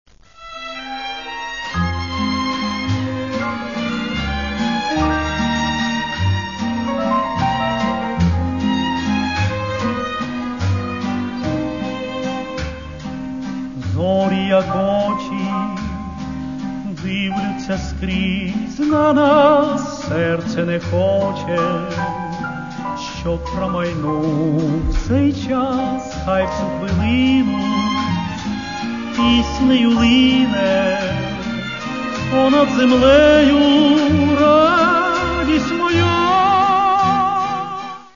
Каталог -> Естрада -> Співаки
Це можна назвати українським естрадним ретро.
Чистий, прозорий, легкий.